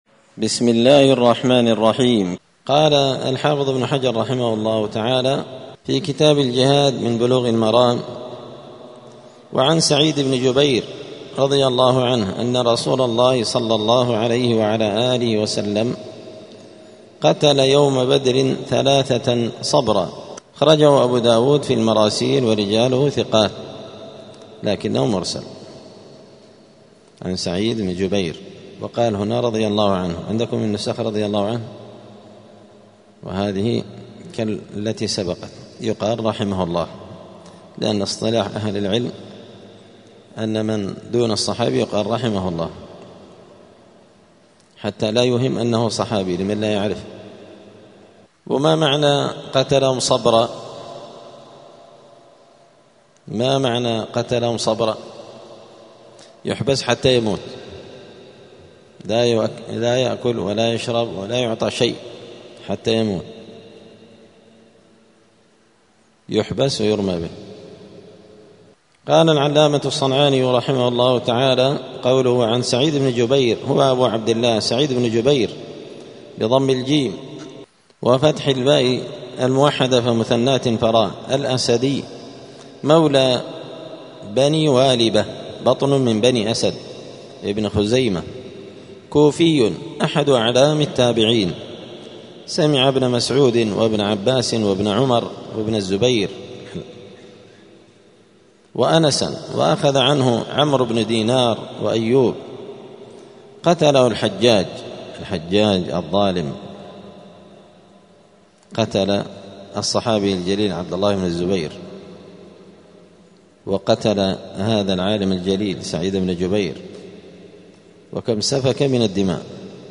*الدرس السادس عشر (16) {باب إقامة الحدود بالحرم}*
دار الحديث السلفية بمسجد الفرقان قشن المهرة اليمن